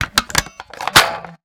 flamethrower_reload_01.wav